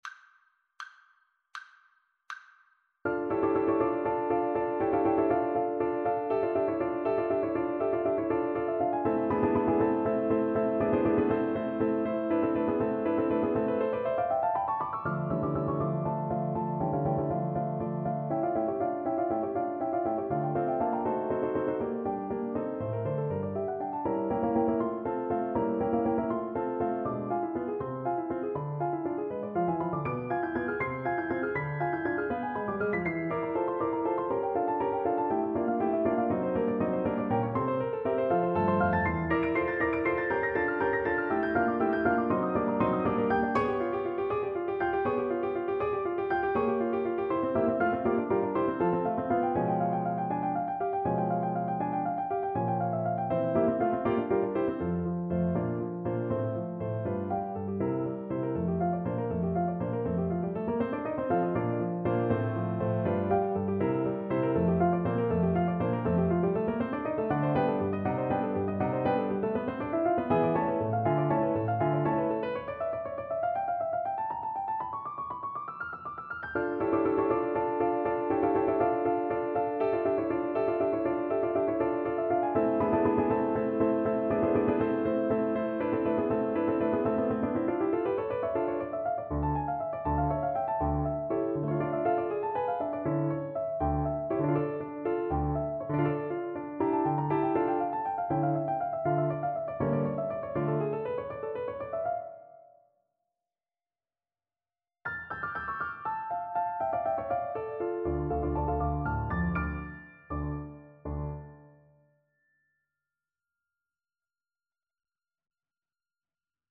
D minor (Sounding Pitch) A minor (French Horn in F) (View more D minor Music for French Horn )
. = 80 Allegro Molto Vivace (View more music marked Allegro)
3/8 (View more 3/8 Music)
Classical (View more Classical French Horn Music)